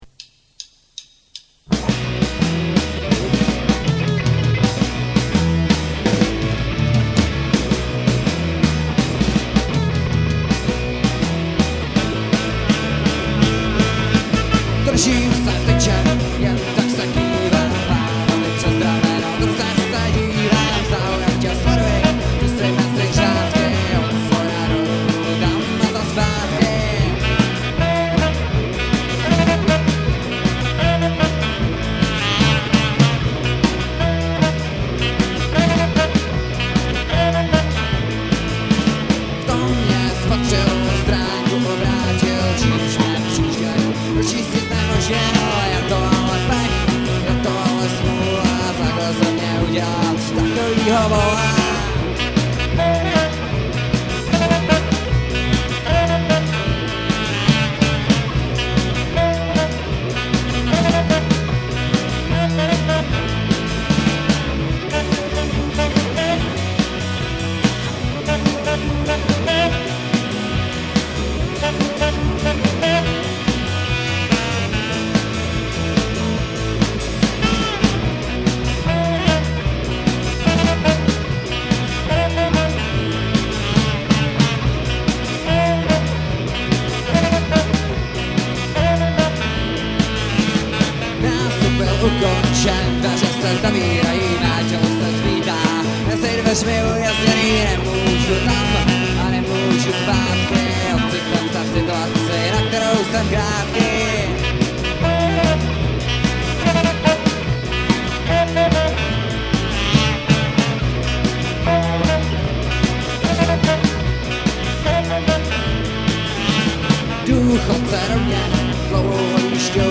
Nahráno živě na koncertu v klubu Prosek dne 1.3.2001